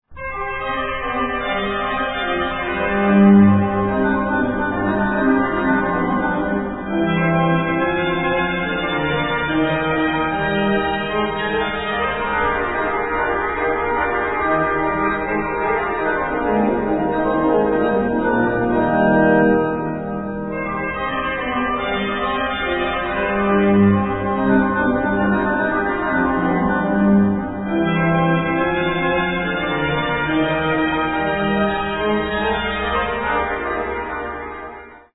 Presto - 1:41